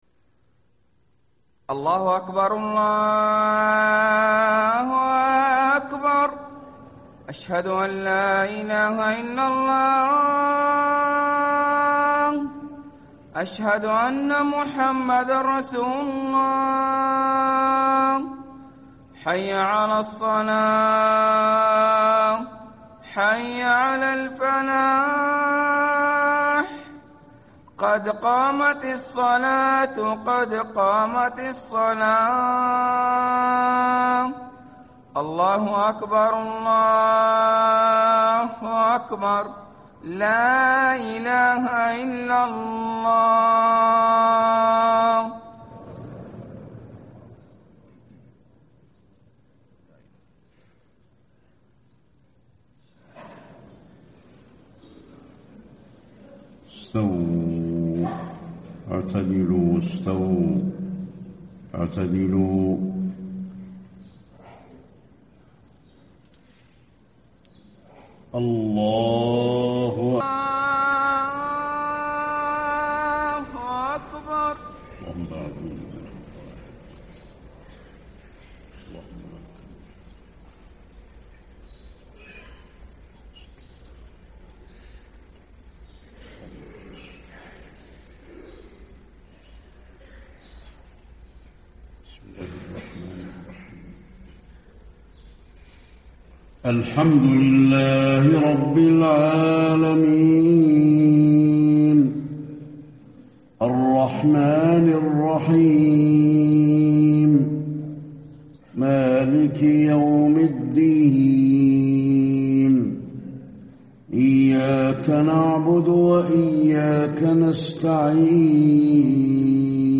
صلاة الفجر 6-6-1435 ما تيسر من سورة يس > 1435 🕌 > الفروض - تلاوات الحرمين